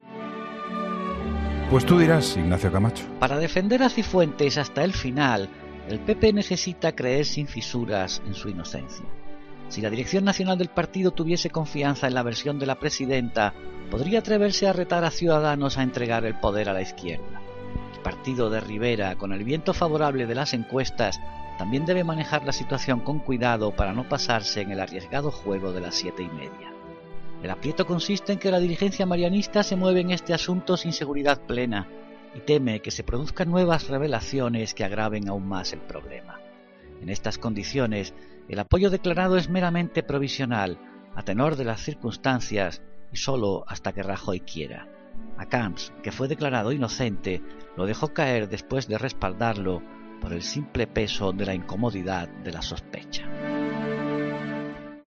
Ignacio Camacho habla en 'La Linterna' de cómo afecta al PP todo el escándalo en torno al máster de Cifuentes